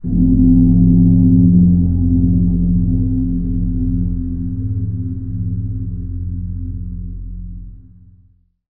其他 " 锣鼓
描述：用动态麦克风制作。